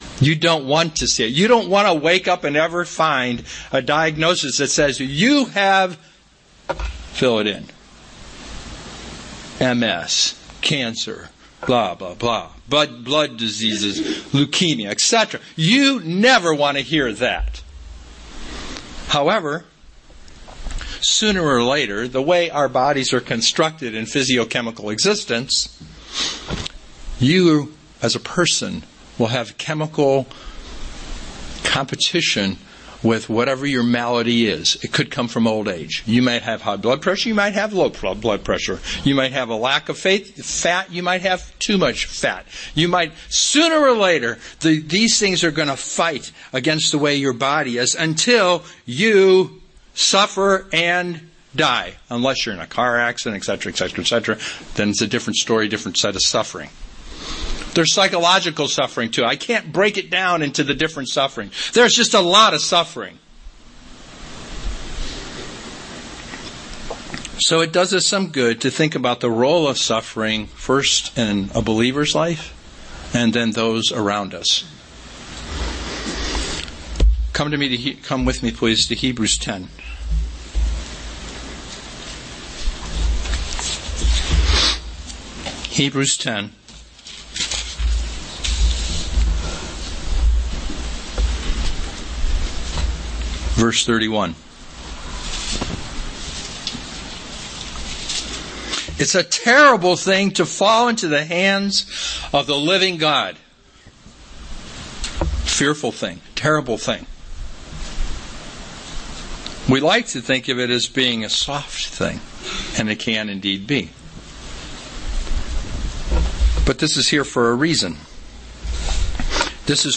Suffering is one of the most important things a Christian has to learn to deal with. (Sorry, but first fifteen minutes are clipped.)
UCG Sermon Studying the bible?